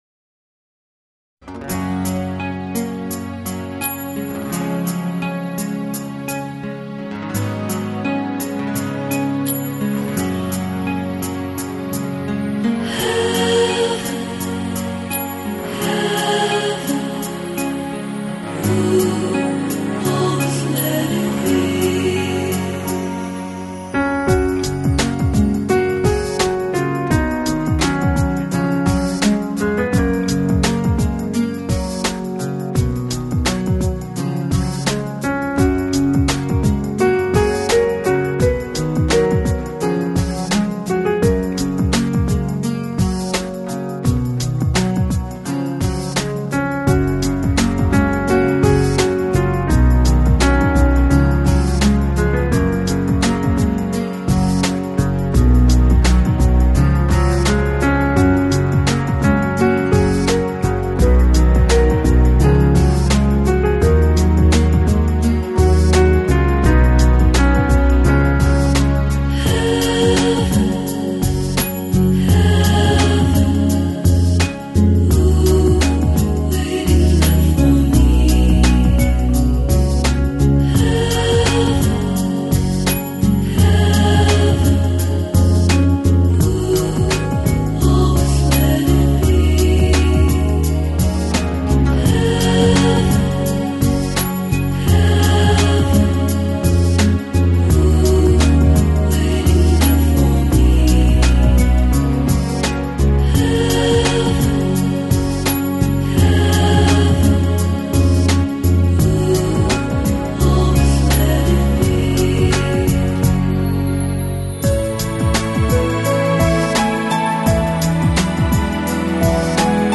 Genre: Celtic
Celtic, Irish folk music performed by popular artists.